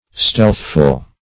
Search Result for " stealthful" : The Collaborative International Dictionary of English v.0.48: Stealthful \Stealth"ful\ (-f[.u]l), a. Given to stealth; stealthy.
stealthful.mp3